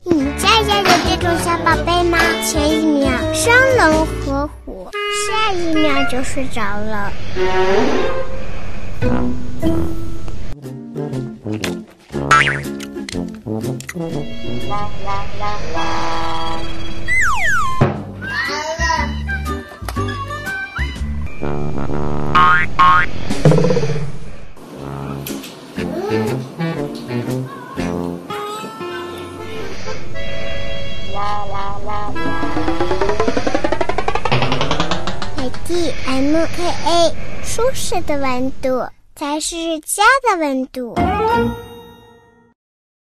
女267-童声 美的MKA空调
女267专题广告解说彩铃 v267
女267-童声----美的MKA空调.mp3